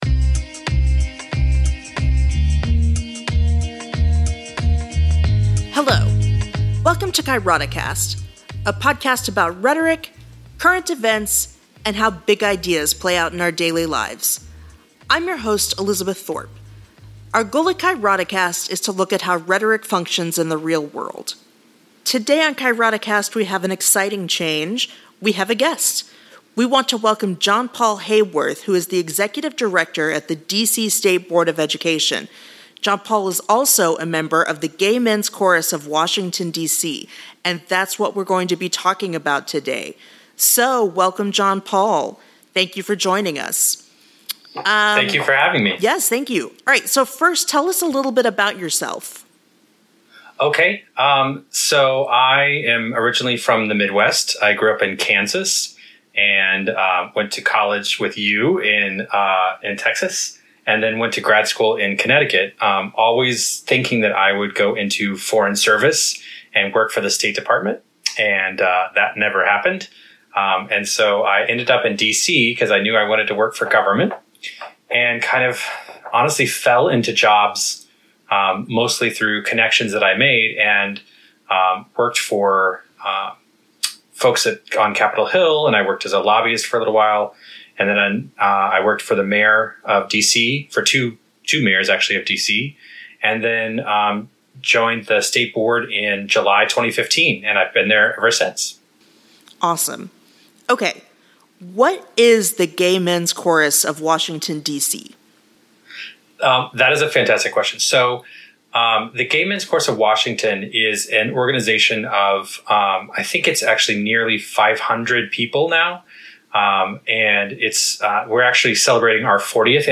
Because this is an interview, and therefore a different sort of episode for us, there is not transcript this week.